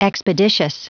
Prononciation du mot expeditious en anglais (fichier audio)
Prononciation du mot : expeditious